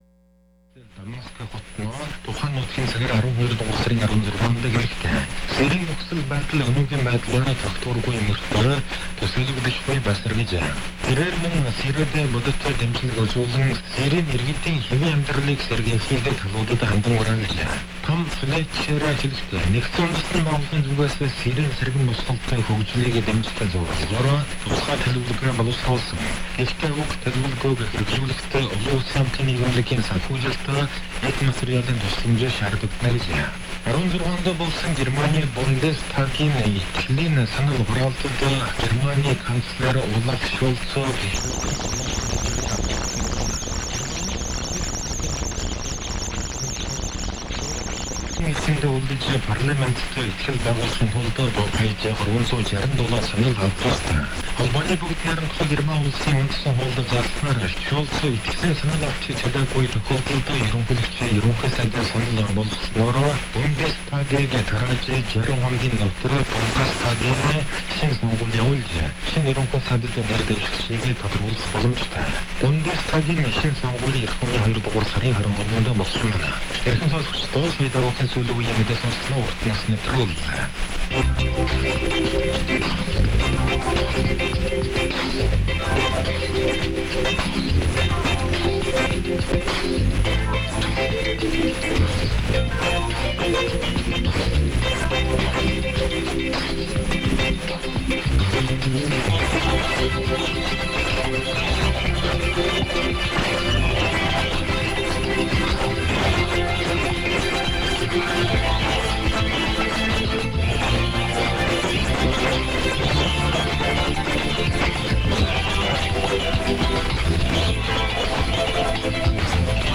Xianyang, CHN transmitter. Good signal into EM80. 58+40 with slight static.
0010 UTC - OM speaking Mongolian (?)